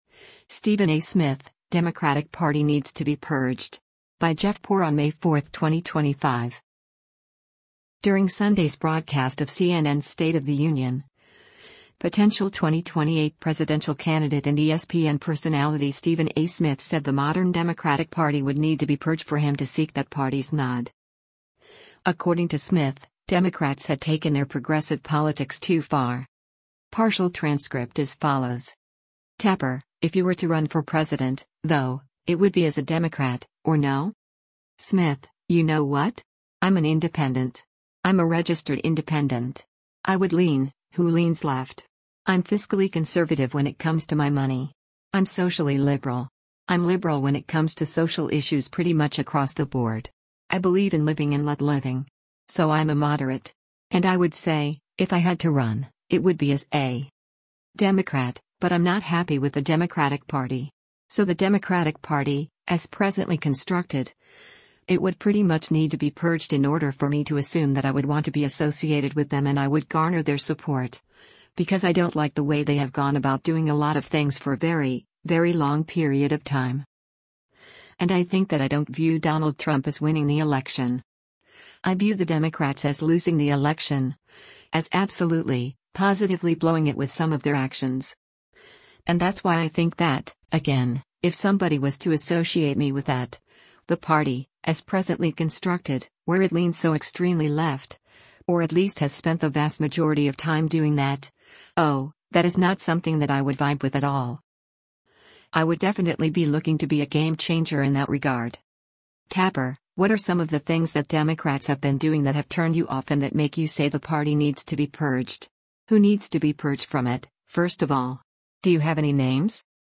During Sunday’s broadcast of CNN’s “State of the Union,” potential 2028 presidential candidate and ESPN personality Stephen A. Smith said the modern Democratic Party would need to be purged for him to seek that party’s nod.